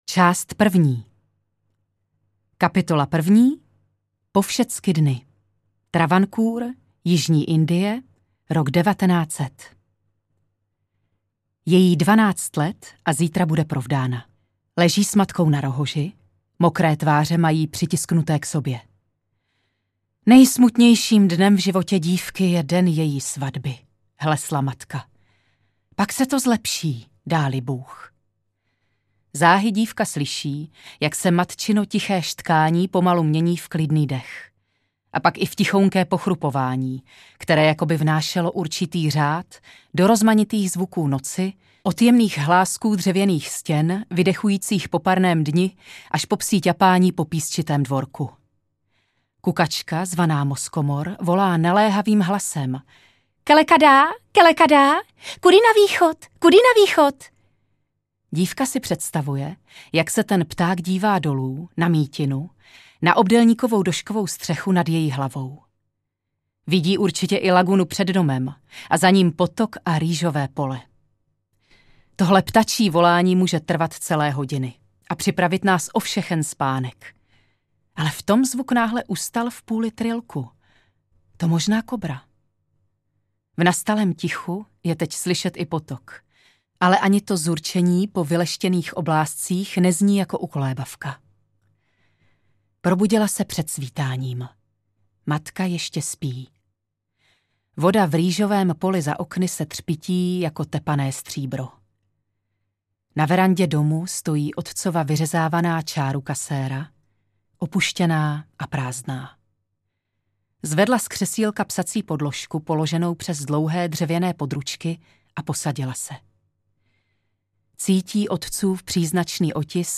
ukázka – audio kniha